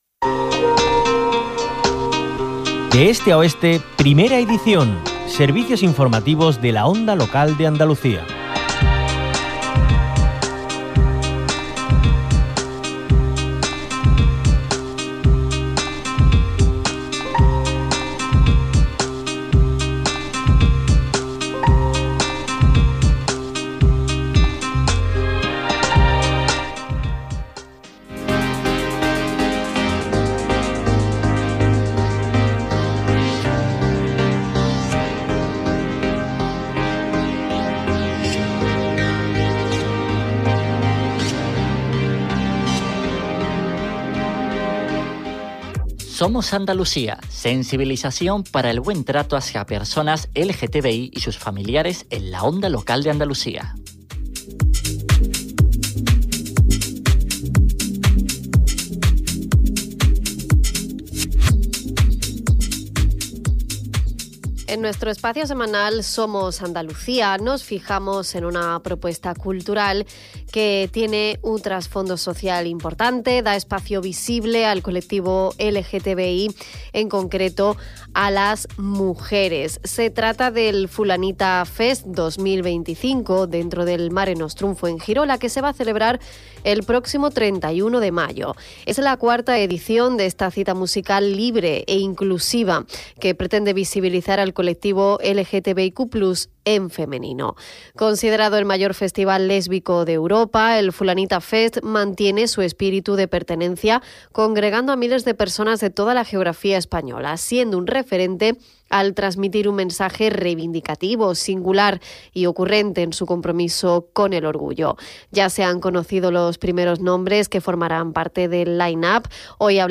(Publicado en Onda Local de Andalucía).